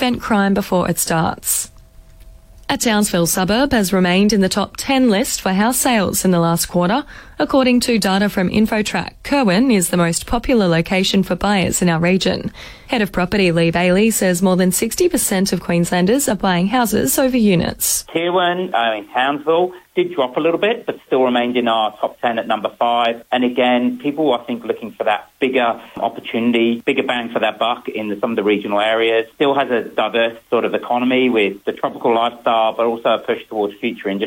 Star 106.3 FM 6:00am News